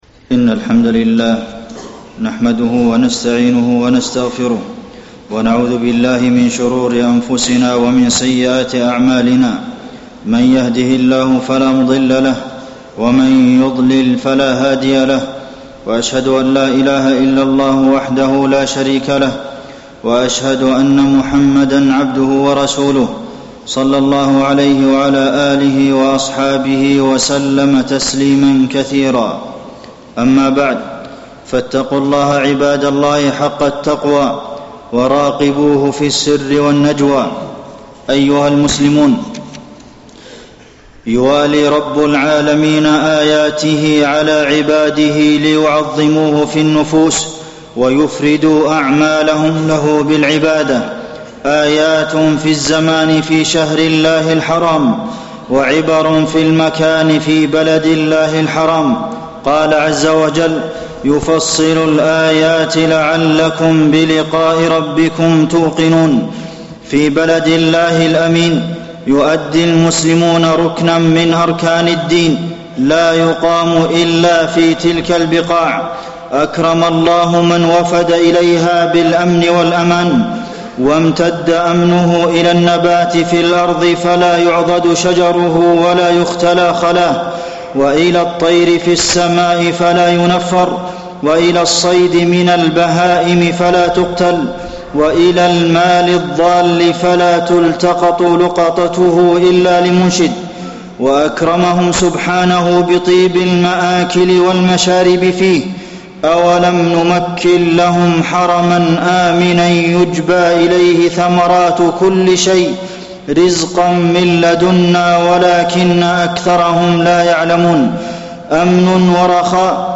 تاريخ النشر ١٠ ذو الحجة ١٤٣٠ هـ المكان: المسجد النبوي الشيخ: فضيلة الشيخ د. عبدالمحسن بن محمد القاسم فضيلة الشيخ د. عبدالمحسن بن محمد القاسم مظاهر العيد